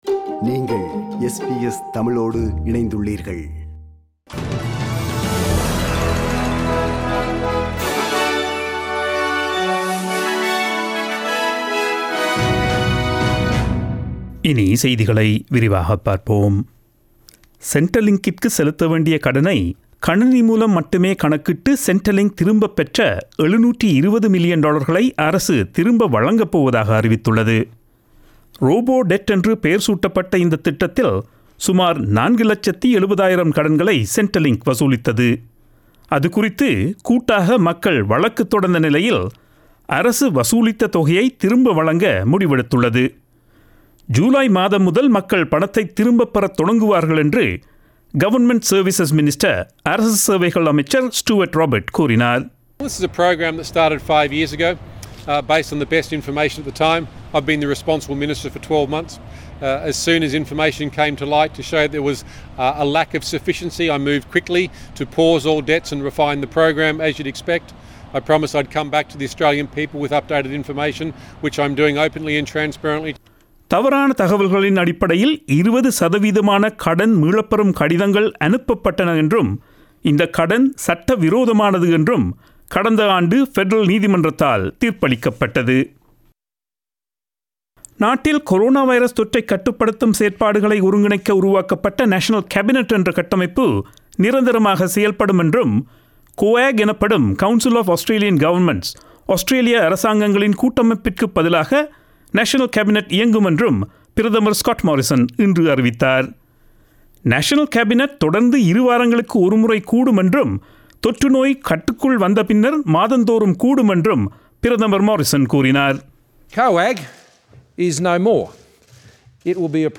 Australian news bulletin aired on Friday 29 May 2020 at 8pm.